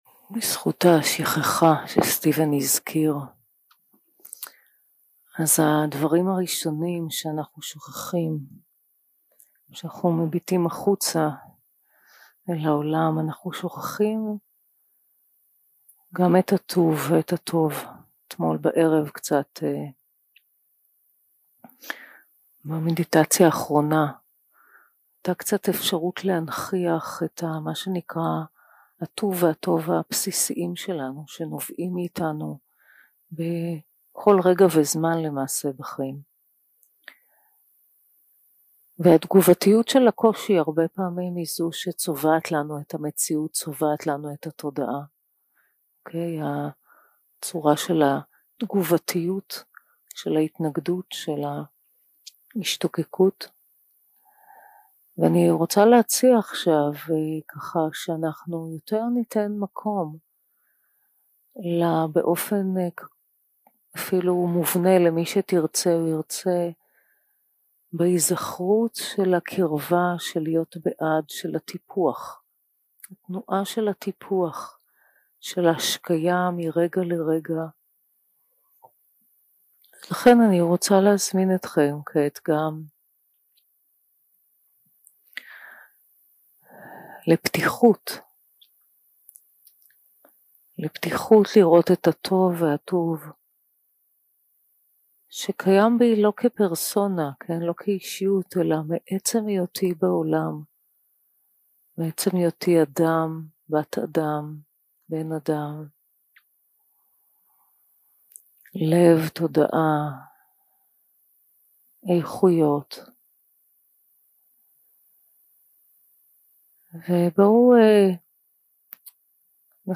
יום 3 – הקלטה 7 – בוקר – מדיטציה מונחית – טיפוח מטא כתנועת השקייה
יום 3 – הקלטה 7 – בוקר – מדיטציה מונחית – טיפוח מטא כתנועת השקייה 0:00 0:00 סוג ההקלטה: Dharma type: Guided meditation שפת ההקלטה: Dharma talk language: Hebrew